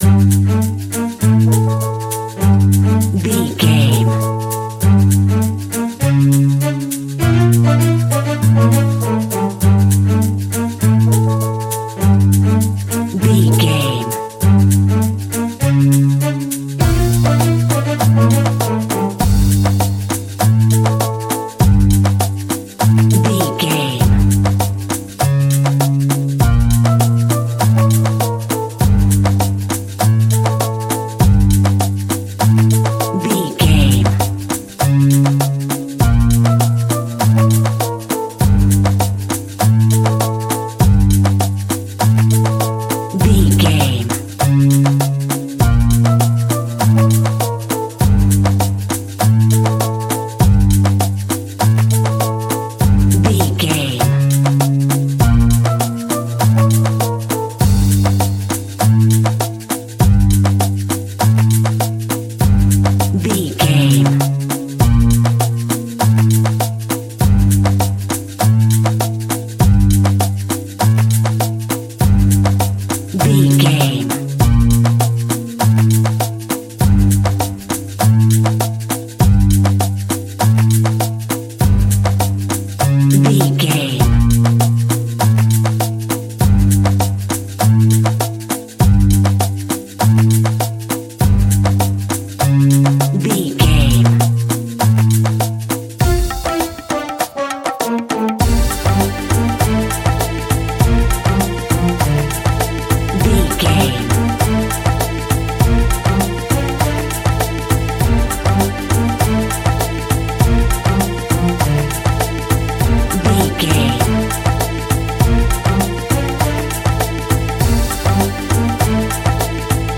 Aeolian/Minor
instrumentals
World Music
percussion